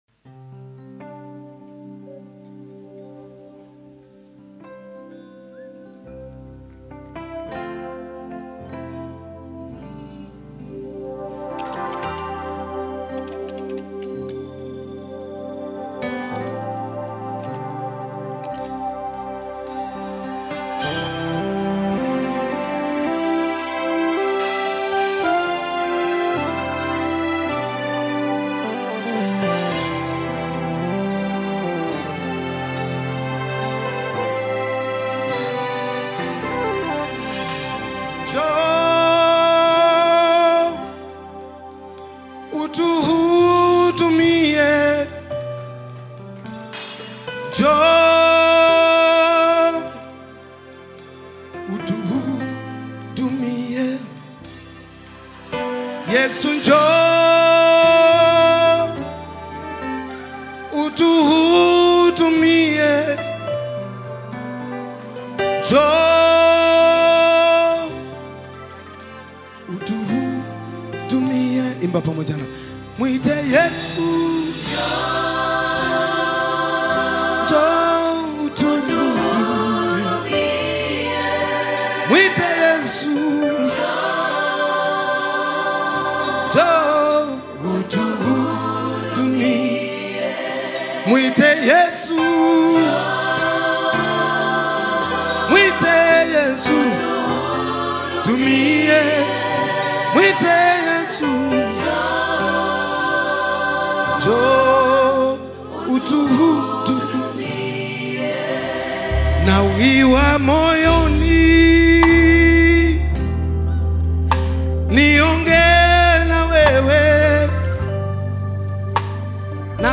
African Music
gospel song